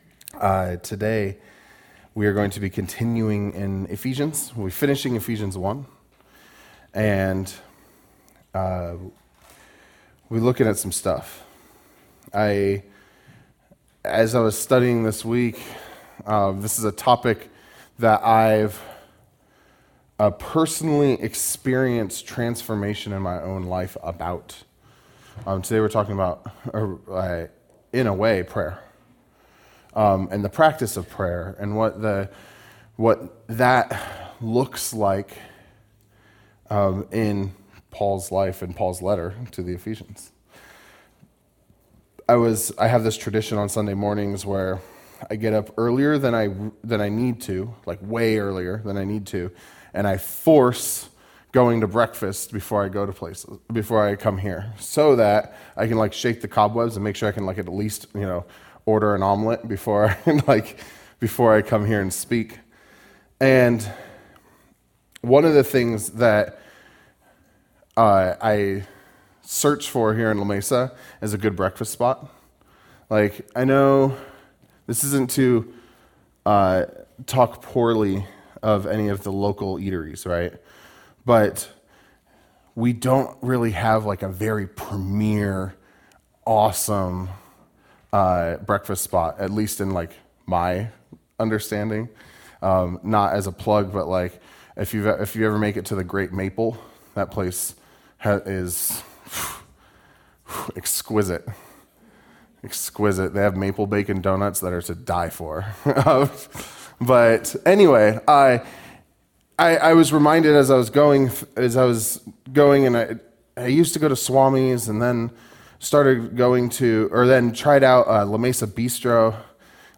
A message from the series "Ephesians."